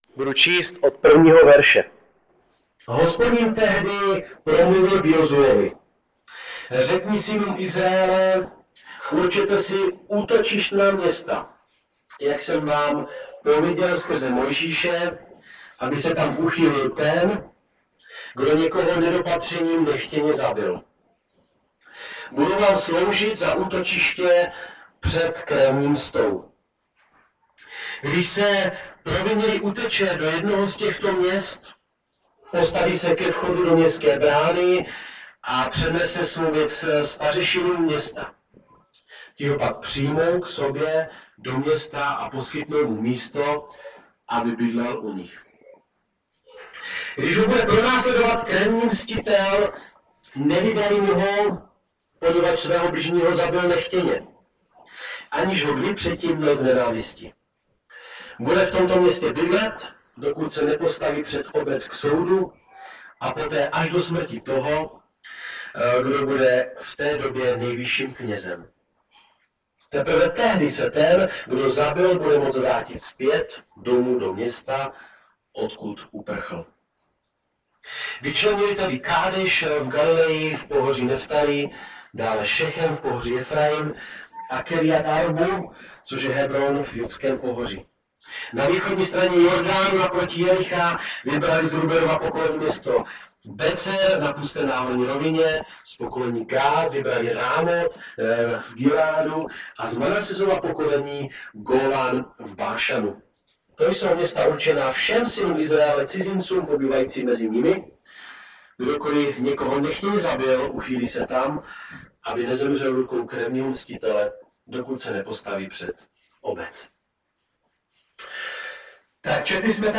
Omluvte sníženou kvalitu zvuku.